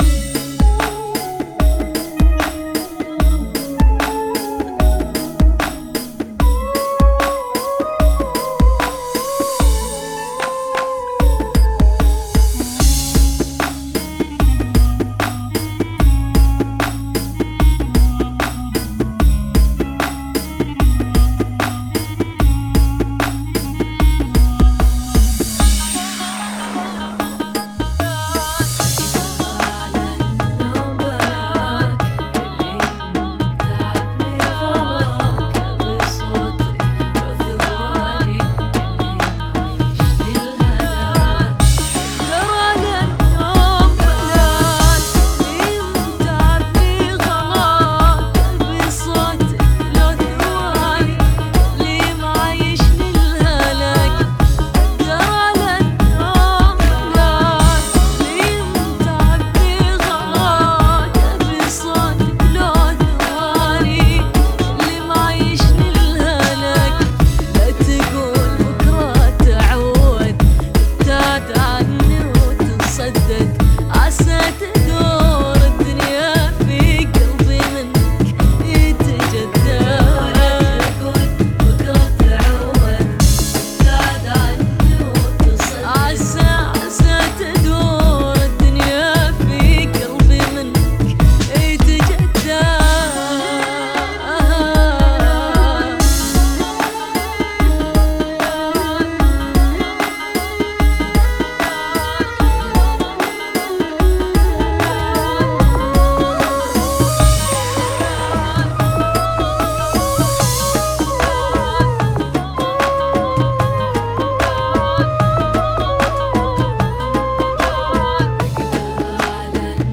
Funky [ 75 Bpm ]